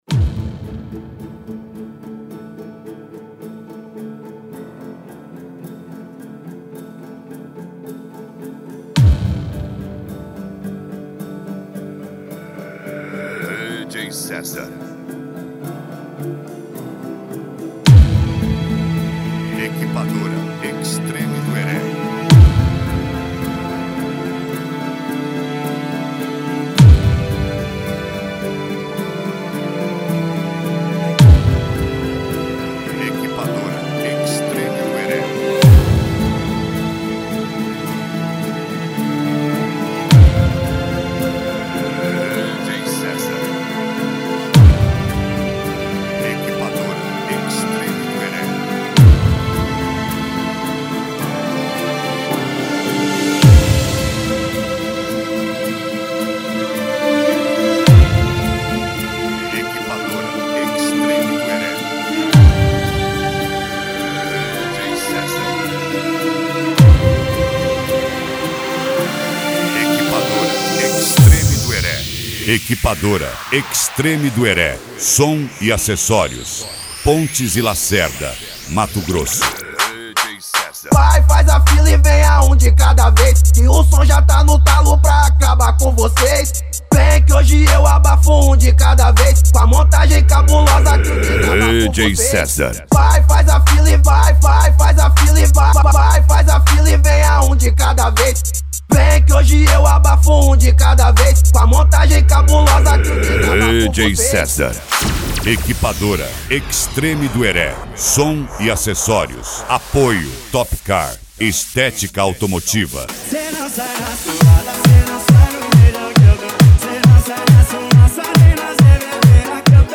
Deep House
Funk
Mega Funk
SERTANEJO